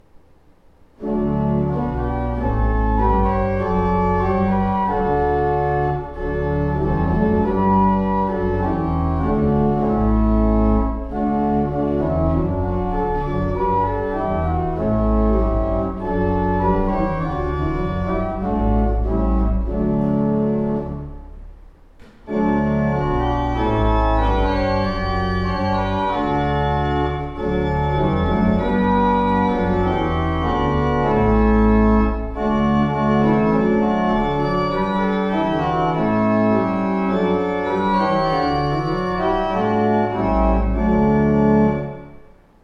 Organist Gloucestershire, UK